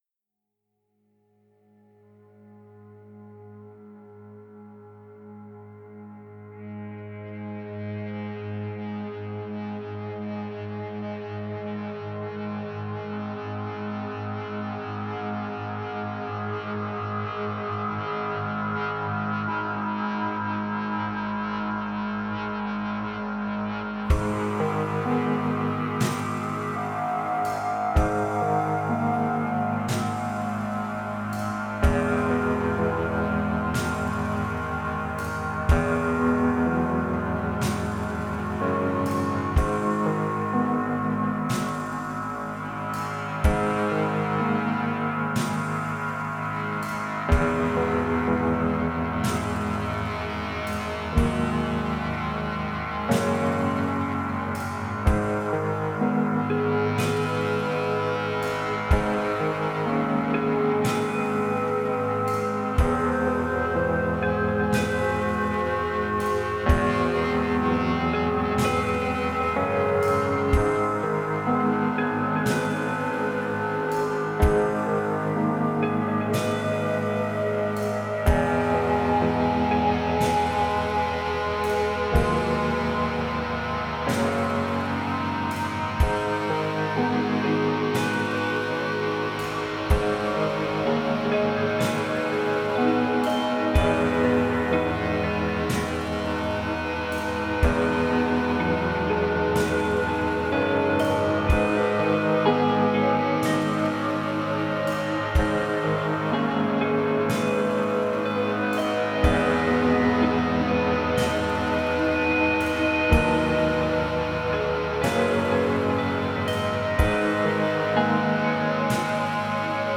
Жанр: Post-Metal.